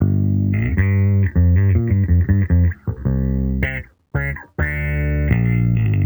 Index of /musicradar/sampled-funk-soul-samples/79bpm/Bass
SSF_JBassProc2_79G.wav